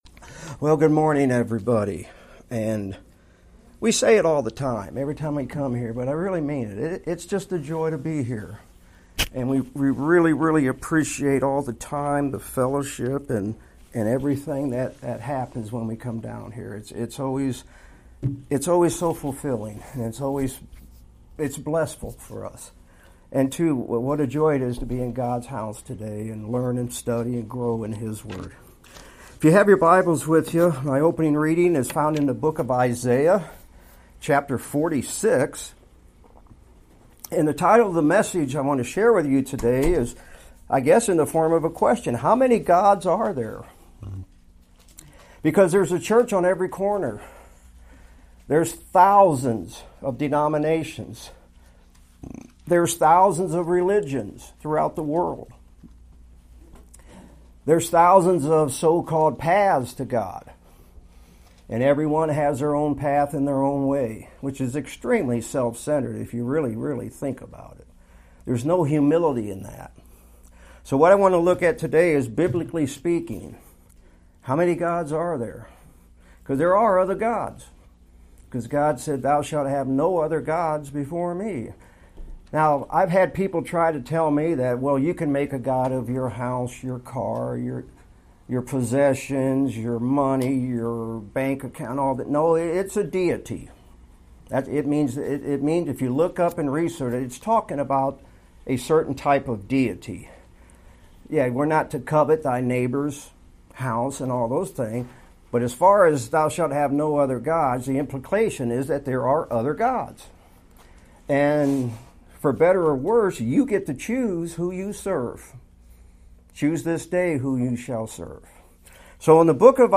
2025 Sermons Visiting Pastor Sermons